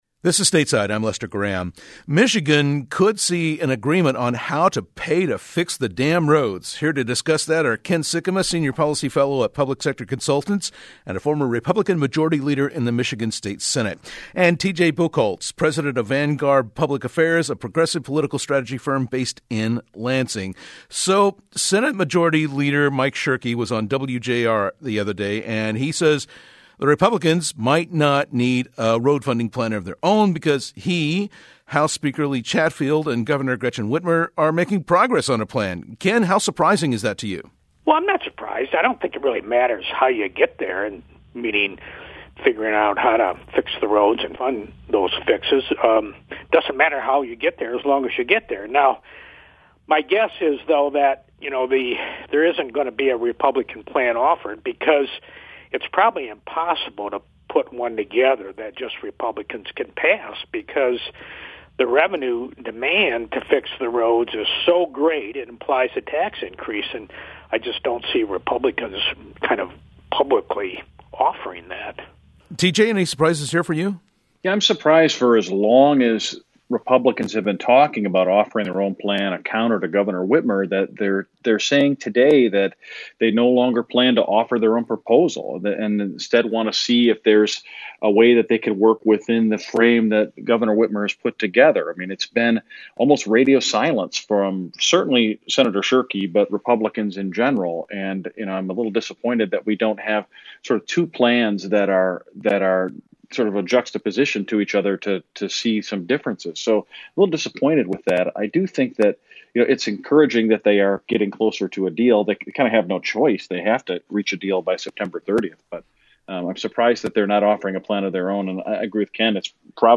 Stateside’s conversation